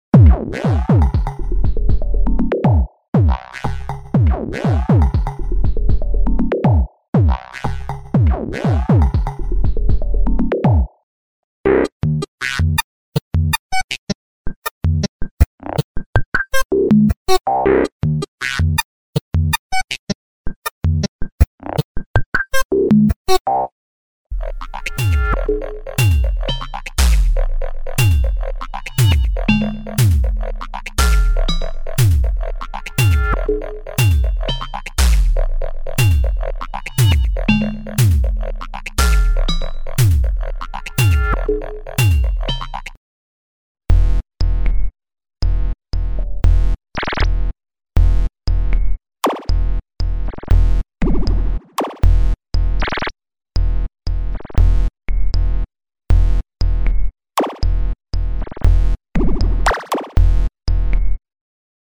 Electronic percussion audio samples
Even though it features plenty of kick drums, some snares and toms, the bulk of this set is made of original and unique percussive sounds and FX.
All were created with various softsynths.
Patterns with only samples from the set: